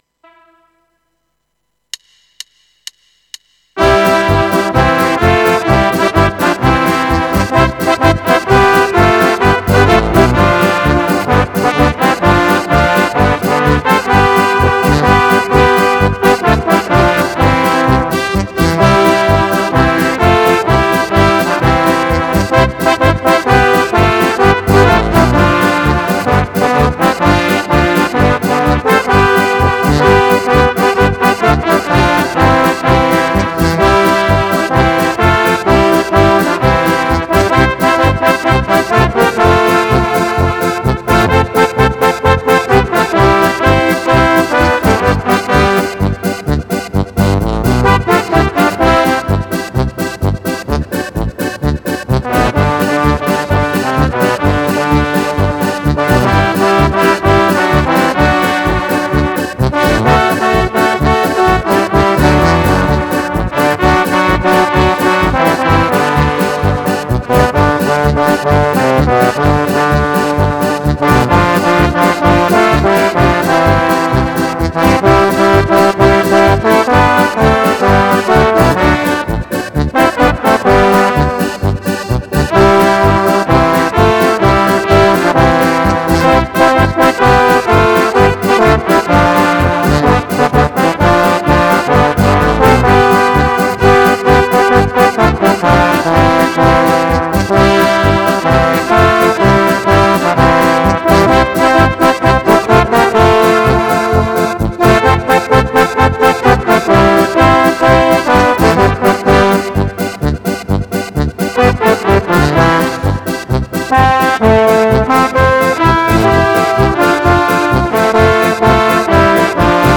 Besetzung: Blasorchester
Medley mit bekannten Titeln